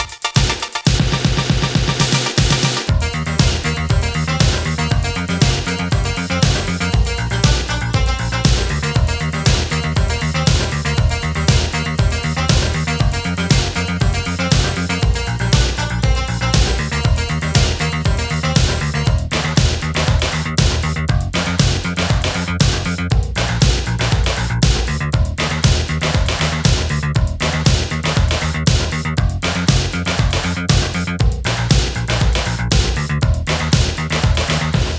Жанр: Рок / Танцевальные / Альтернатива / Электроника